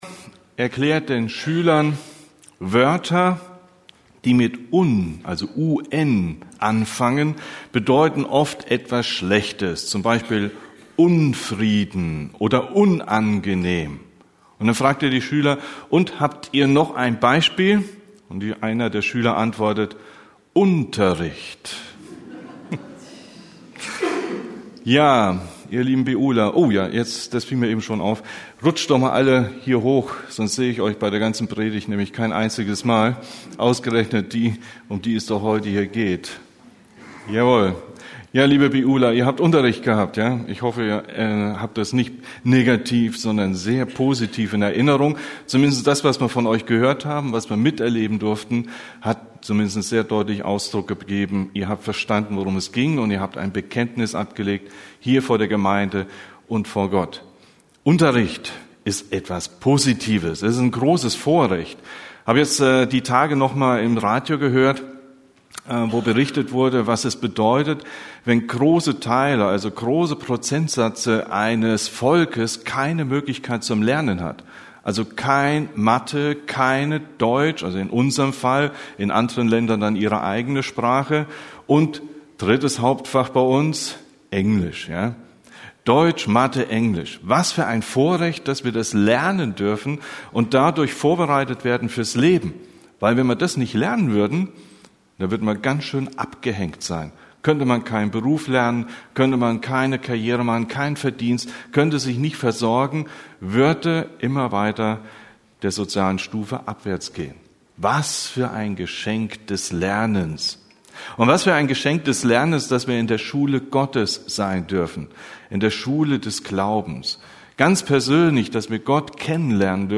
In der Schule des Glaubens – Predigten: Gemeinschaftsgemeinde Untermünkheim